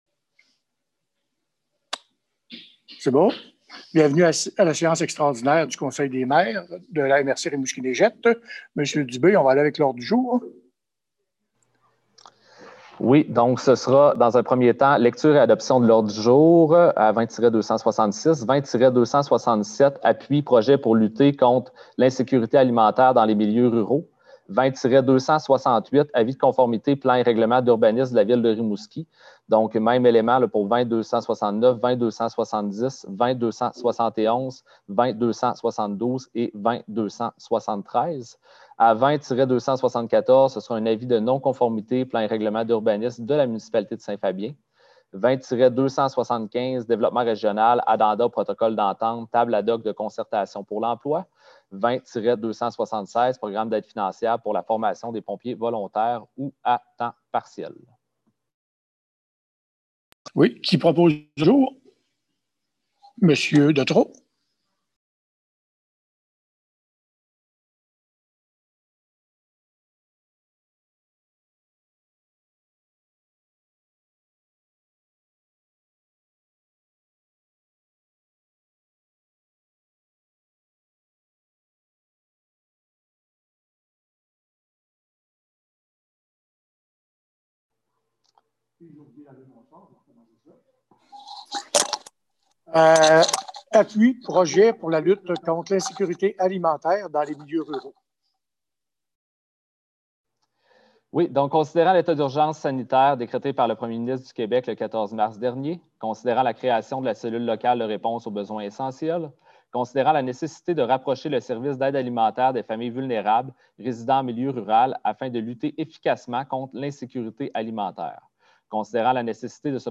Voici l’extrait audio de la séance extraordinaire du conseil du 28 octobre 2020